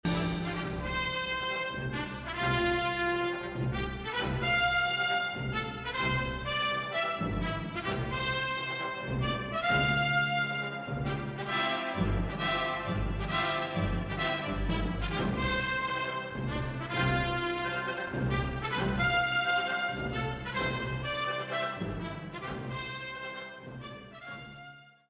en attendant pour patienter, voici le générique du podcast